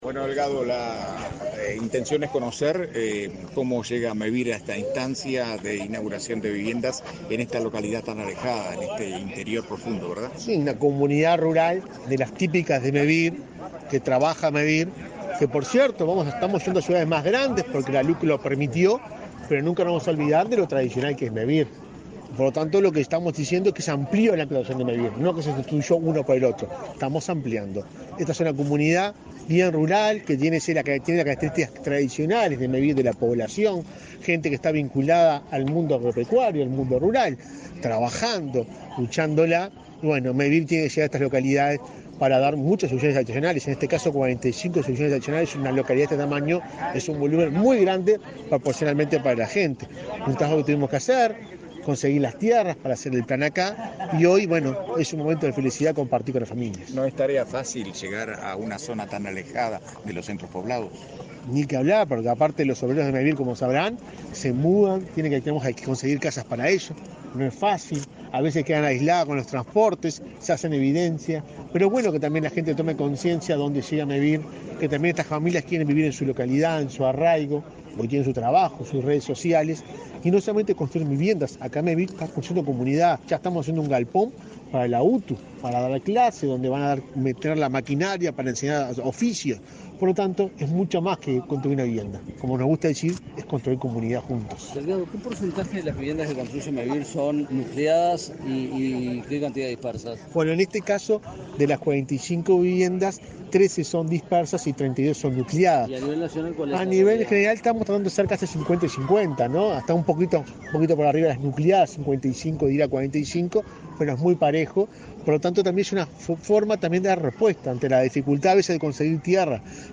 Declaraciones a la prensa del presidente de Mevir, Juan Pablo Delgado
Declaraciones a la prensa del presidente de Mevir, Juan Pablo Delgado 15/02/2023 Compartir Facebook X Copiar enlace WhatsApp LinkedIn Tras la inauguración de viviendas de Mevir en la localida de Árevalo, departamento de Cerro Largo, este 14 de febrero, el presidente de Mevir, Juan Pablo Delgado, realizó declaraciones a la prensa.